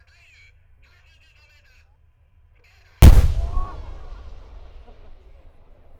ひゃ～、数万人いた会場の話し声や場内放送がほとんど聴こえないレベルで録音してたのに、余裕でクリップしてる。。
※最前列から数十mくらいから、Roland R09で16bit／44．1kHz wavファイル録音してます。クリップしてない爆発音もあったのですが、あえて"無茶苦茶な臨場感"が伝わるクリップファイルを上げてます。
今回は鑑賞メインだったので、録音状態も適当なんでご容赦を。
戦車砲撃１
特筆すべきなのは、ほぼ直角でピークに達する恐ろしい瞬発力。